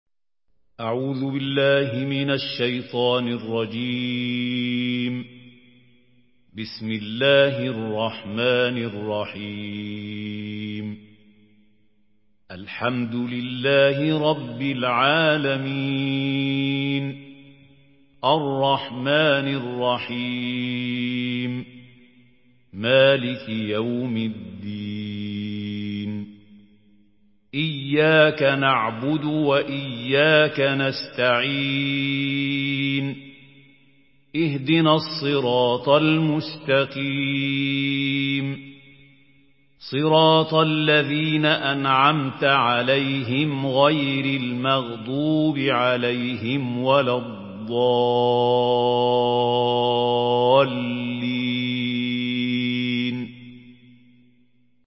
سورة الفاتحة MP3 بصوت محمود خليل الحصري برواية حفص
مرتل حفص عن عاصم